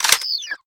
gauss_holster.ogg